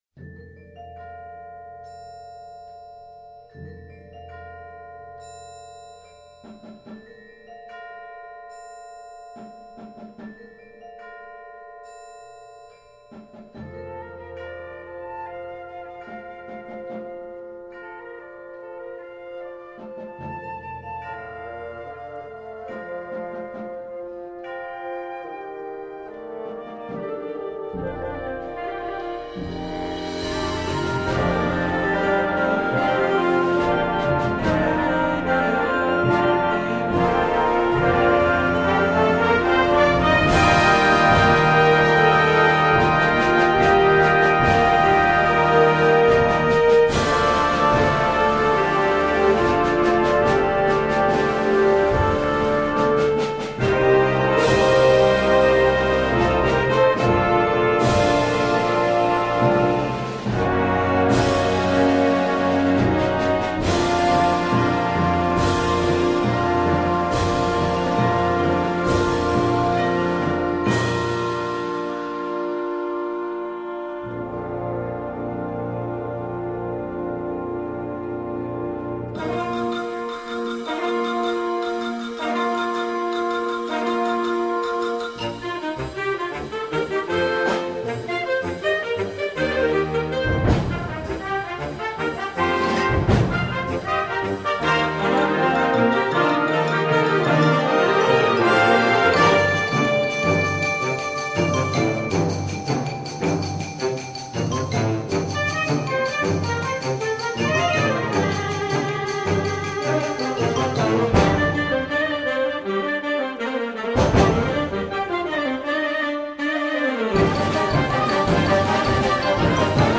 Categorie Harmonie/Fanfare/Brass-orkest
Subcategorie moderne populaire muziek
Bezetting Ha (harmonieorkest)